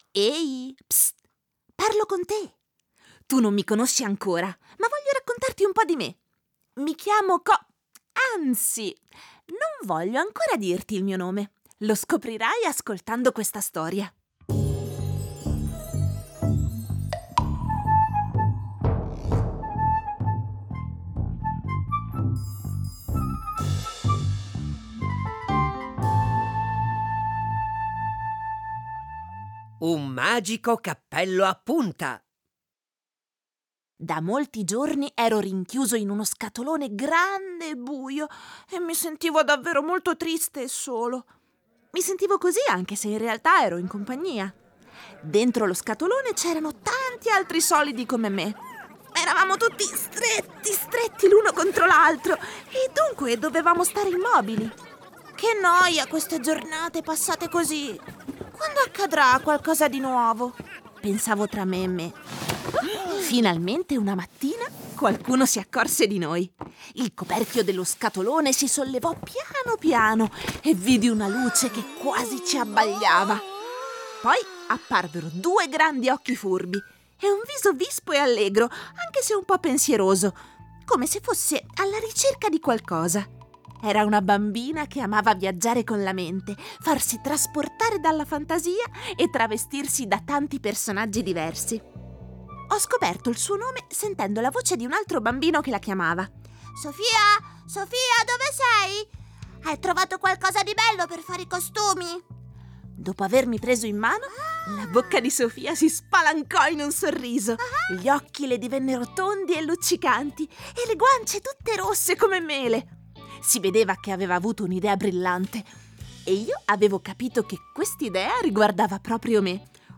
RSI KIDS in collaborazione con il Dipartimento formazione e apprendimento della SUPSI ha creato 10 storie che hanno come protagoniste le figure geometriche.